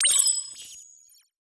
Technology Notification 1.wav